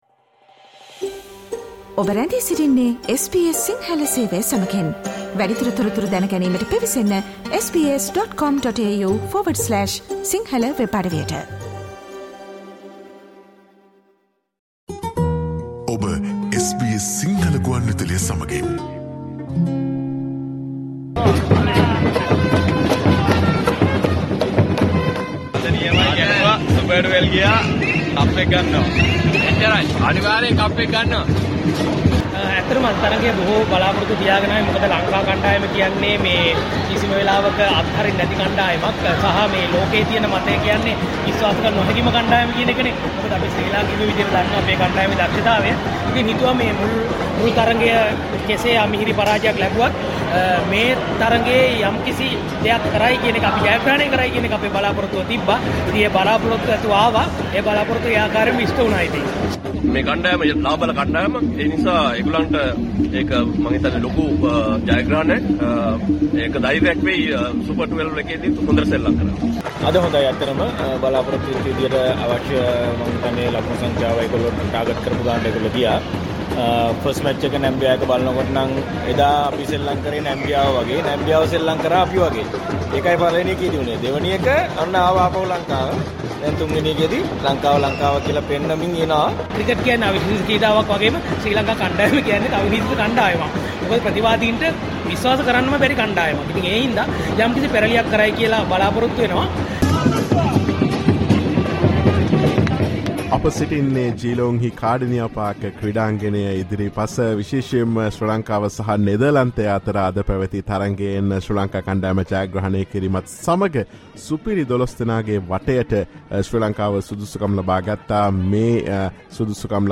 After qualifying for the Super 12 of this T20 World Cup, how will Sri Lanka's progress in this tournament be? Listen to the discussion conducted by SBS Sinhala Radio